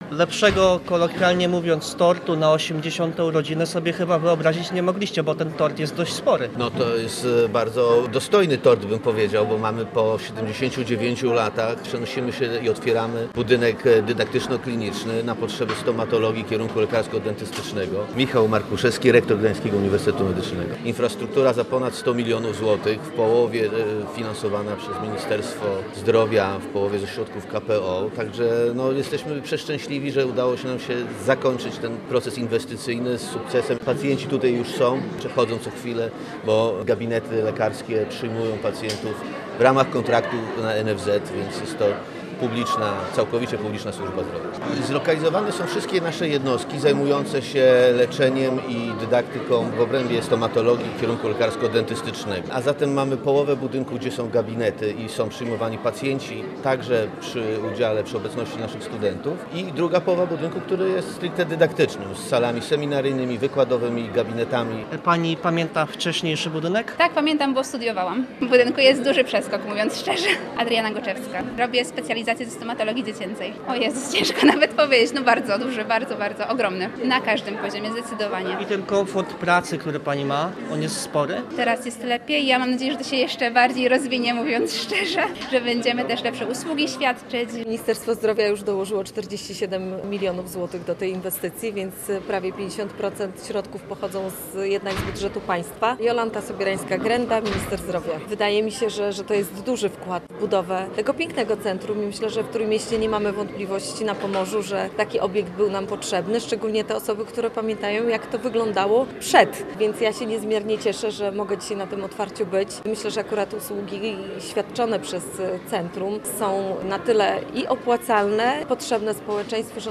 Posłuchaj materiału reportera: